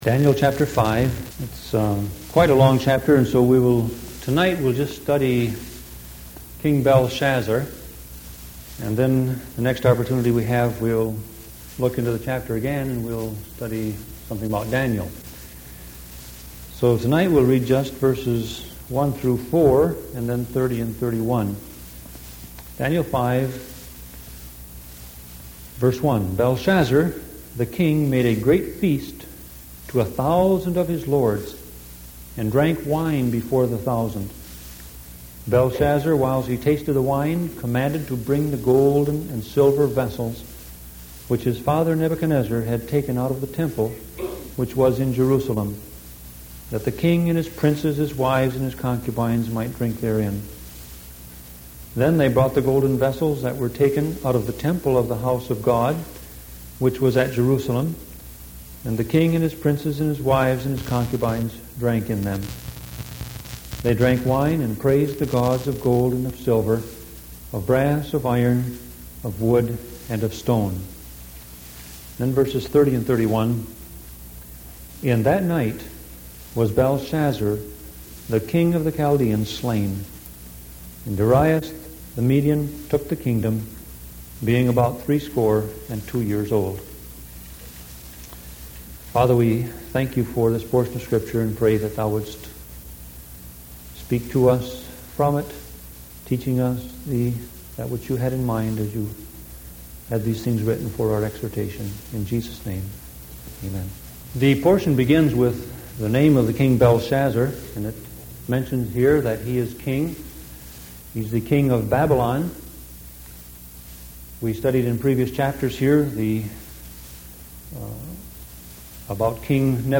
Sermon Audio Passage: Daniel 5:1-4:30 Service Type